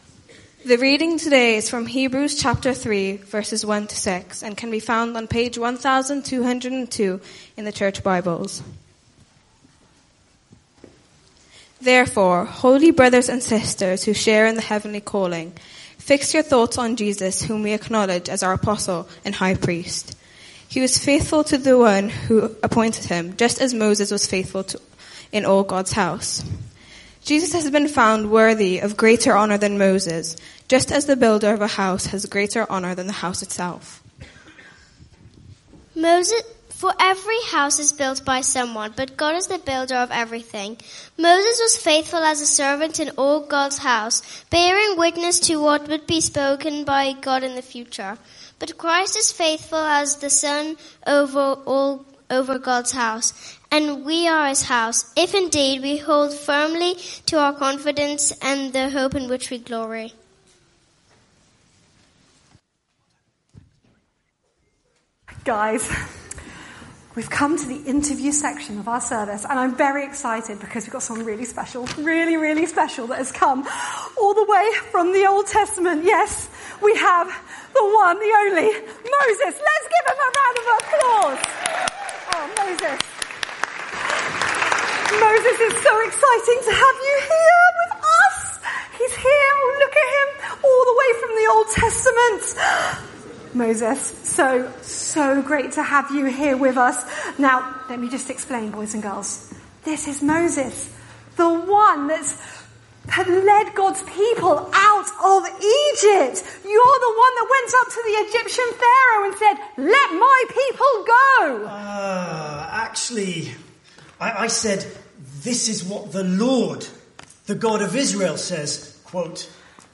Hebrews 3:1-6; 03 November 2024, Morning Service.